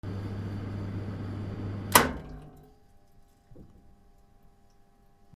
ファンヒーター スイッチ切る
/ M｜他分類 / L10 ｜電化製品・機械